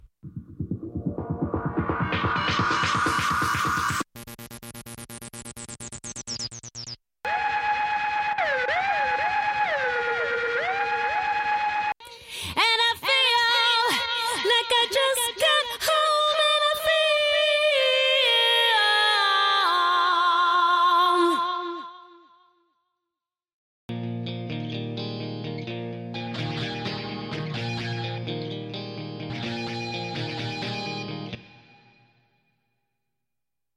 Studio Bassline Stem
Studio Guitars Stem
Studio Leading Vocals Stem
Studio Percussion & Drums Stem
Studio Synths Stem